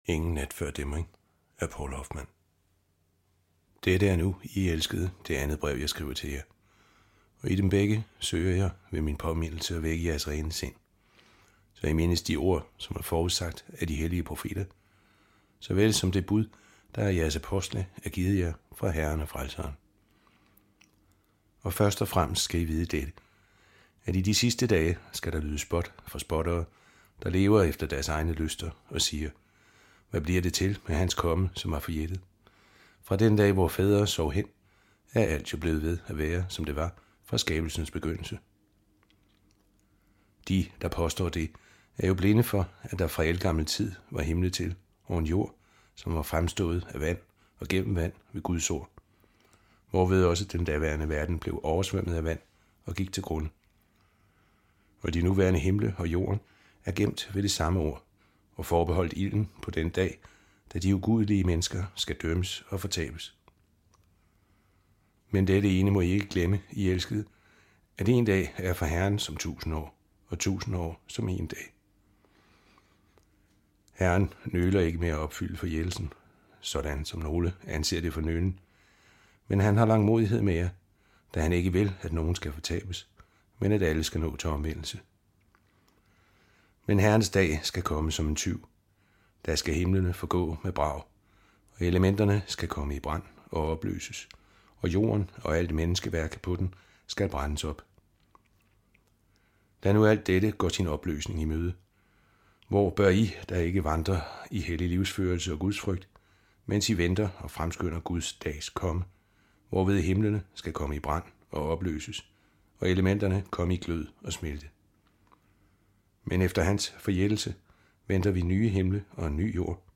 Hør et uddrag af Ingen nat før dæmring Ingen nat før dæmring Noas dage II Format MP3 Forfatter Poul Hoffmann Lydbog E-bog 149,95 kr.